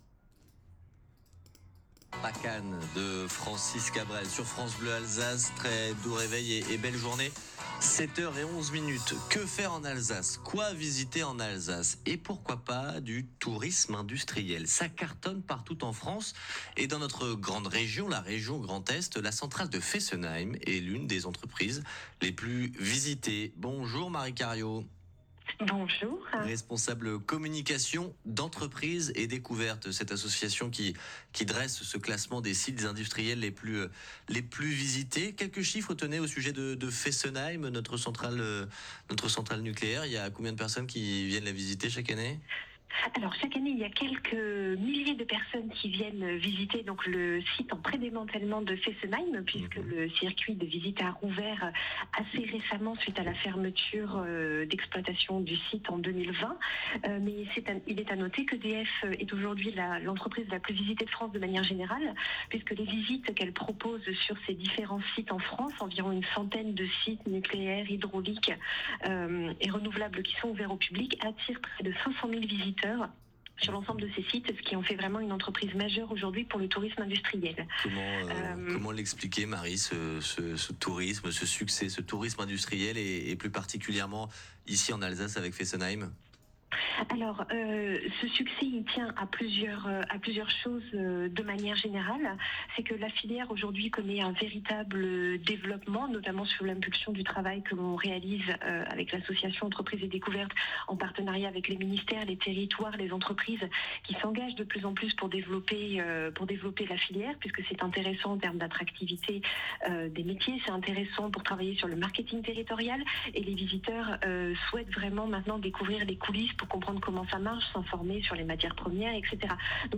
🎙 Interviews radio